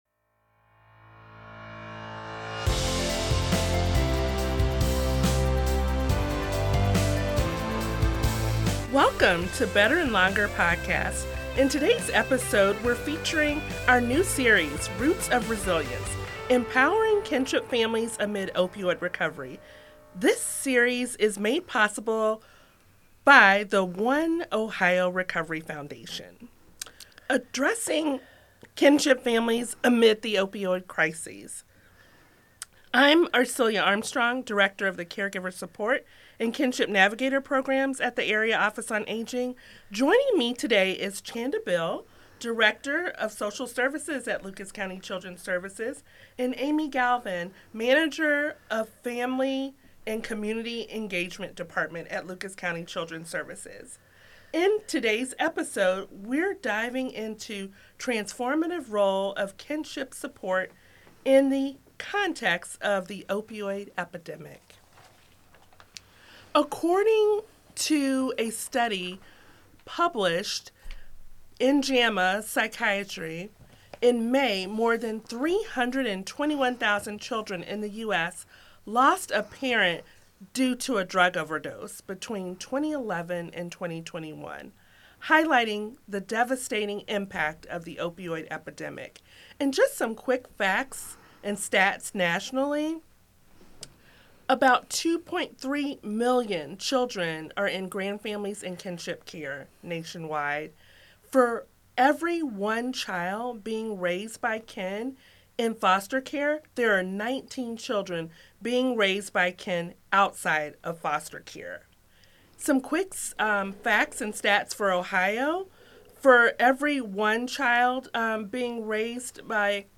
This podcast explores the impact of the opioid epidemic on kinship and grandfamilies in Lucas County. Featuring Lucas County Children Services administrators, it highlights innovative programs like Area Office on Aging’s Kinship Navigator Program, KGAP, Ohio Start, and Kinnect to Family and discusses expanded partnerships and resources supporting families affected by substance use. The episode celebrates declines in children in custody, showcases grant-supported initiatives, and recognizes the dedication of those helping families recover and thrive.